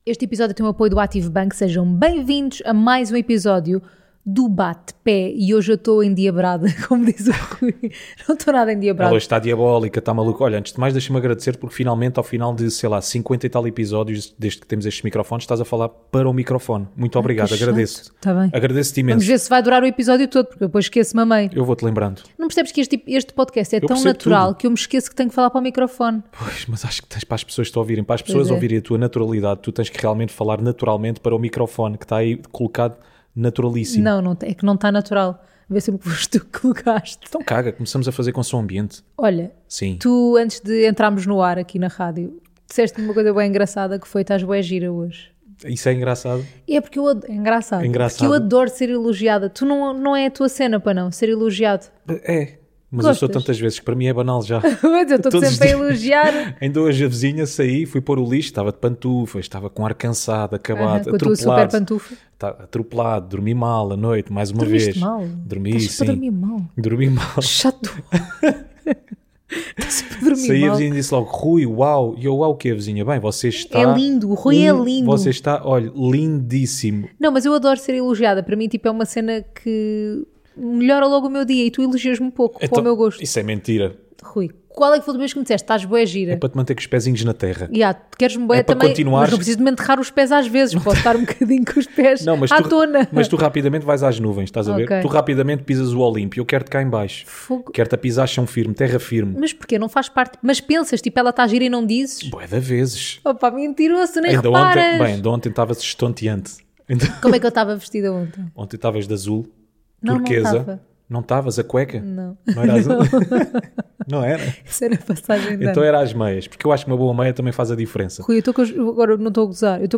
Um casal à conversa sobre temas pouco relevantes para uns e muito pouco relevantes para outros.